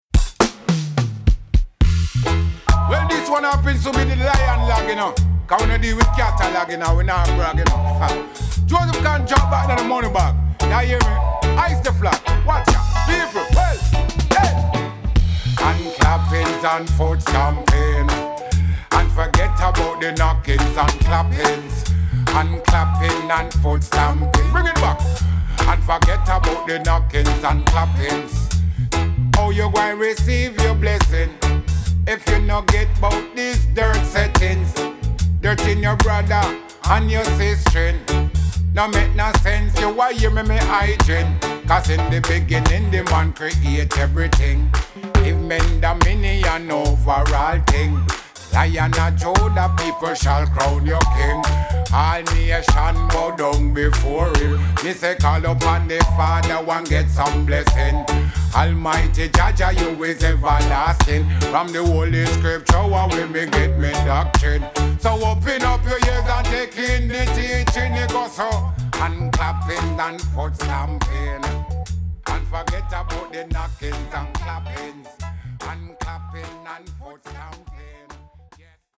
Vocals recorded in Paris.